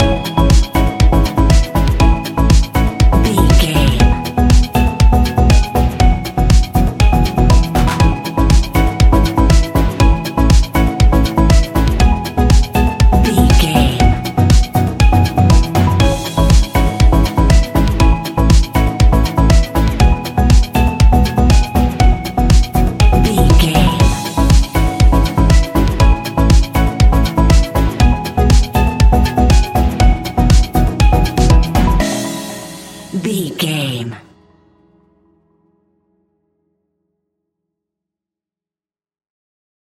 Ionian/Major
house
electro dance
synths
techno
trance